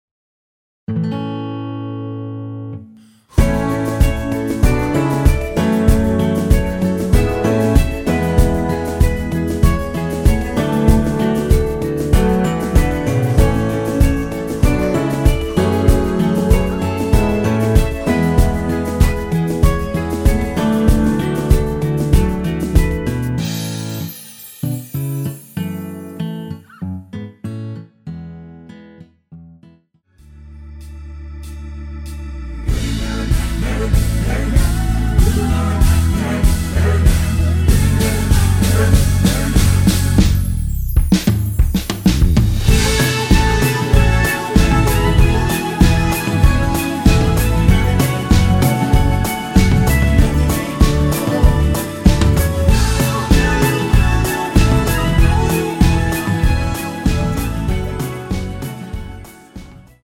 (-1) 내린 코러스 포함된 MR 입니다. 전주 없이 시작 하는 곡이라 1마디 전주 만들어 놓았습니다.
키 A 가수
원곡의 보컬 목소리를 MR에 약하게 넣어서 제작한 MR이며